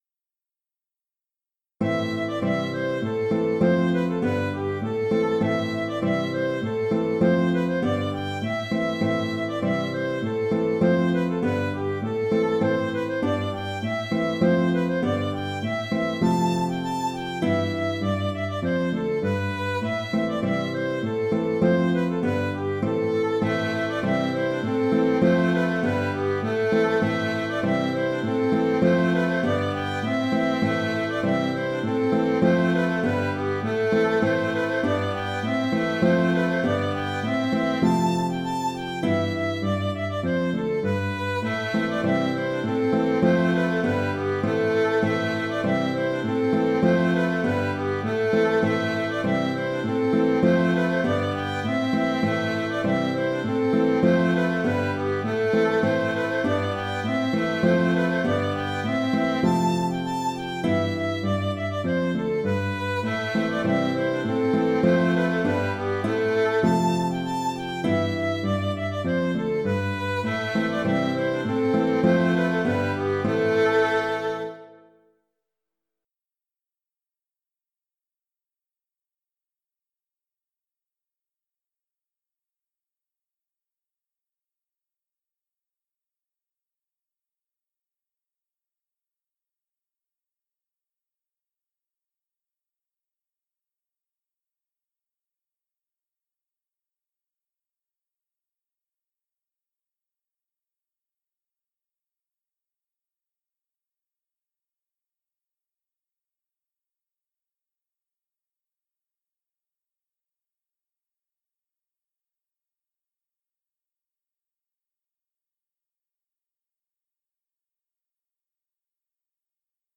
Hanter dro Gwendal (Hanter dro) - Musique bretonne
Dans l’enregistrement audio vous l’entendrez une première fois sans contrechant, puis avec. Il y a deux mesures sans contrechant que nous jouions sur le thème en alternant les instruments.